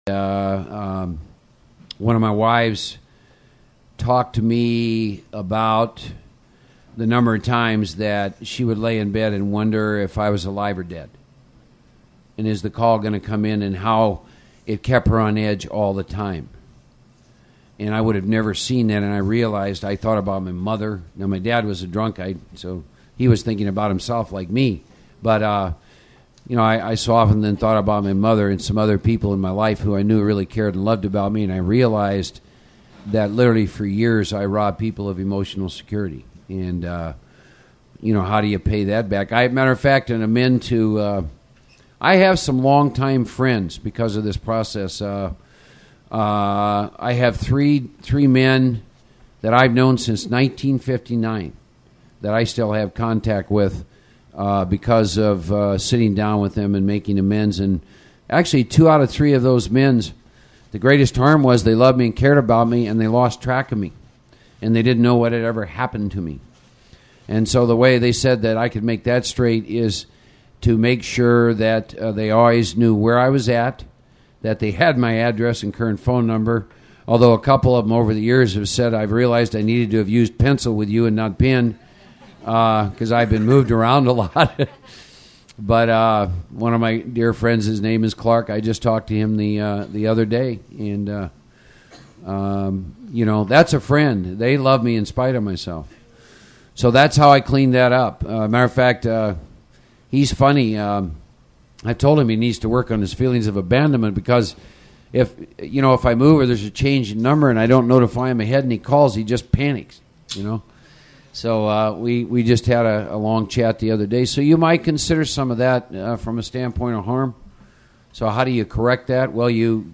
Big Book Experience at the Fellowship of the Spirit | New York City